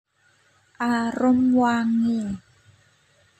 Contoh pengucapan